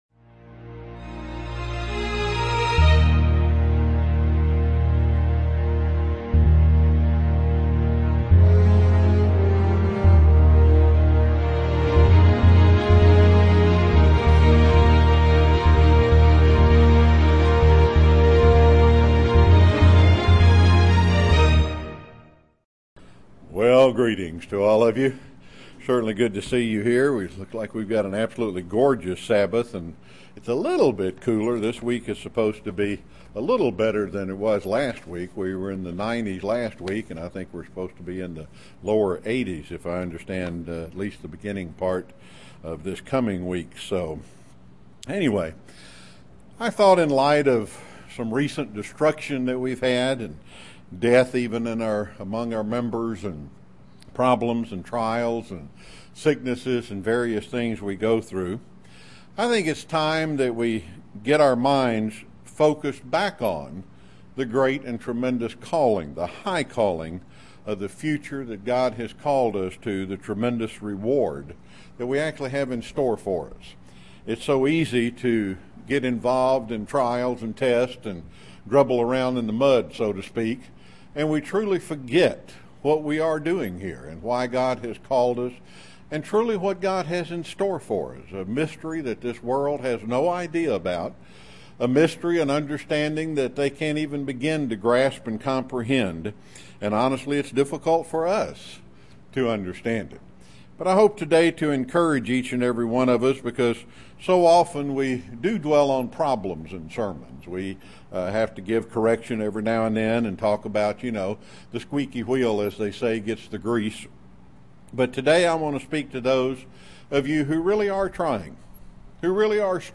Print Your great reward UCG Sermon Studying the bible?
Given in Chattanooga, TN